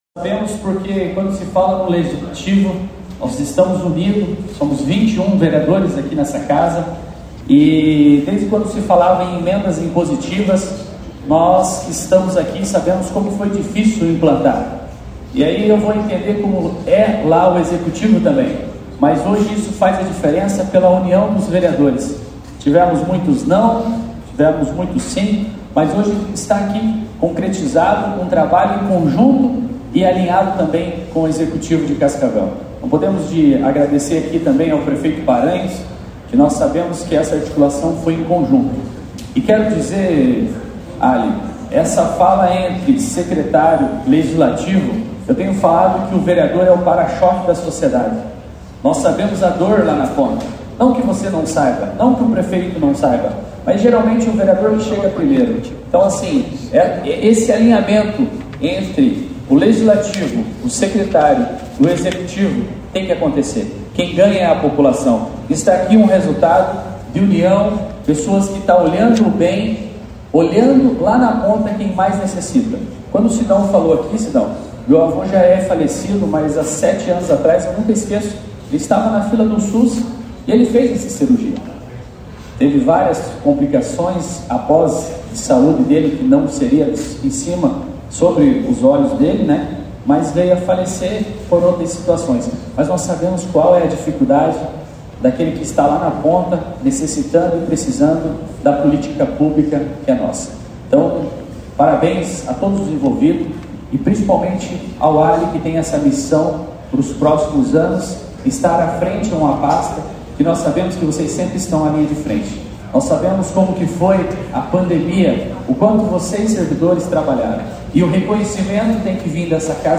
Em evento na Câmara de Vereadores de Cascavel na manhã desta quinta-feira (12), além da destinação de recursos à Saude por parte dos vereadores mediante emendas impositivas, o prefeito eleito Renato Silva anunciu Ali Haidar como secretário de Saúde para a proxima gestão, a partir de janeiro, acompanhe.
Player Ouça Tiago Almeida, vereador